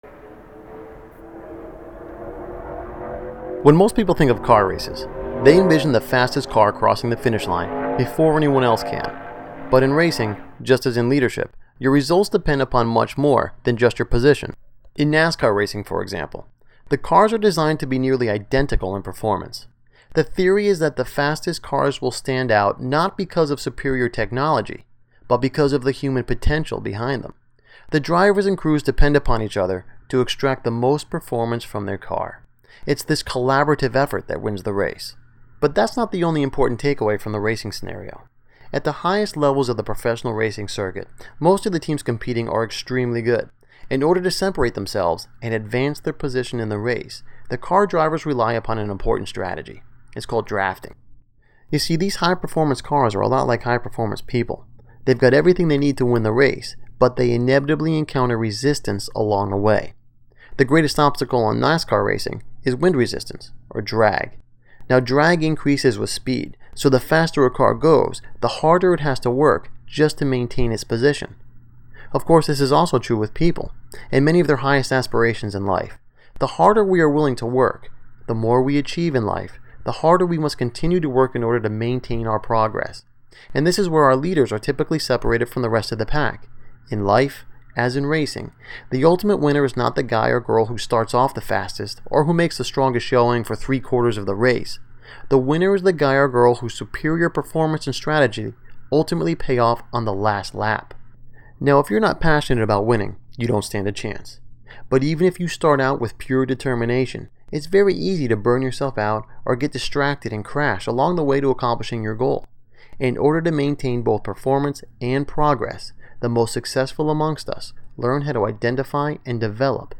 audio book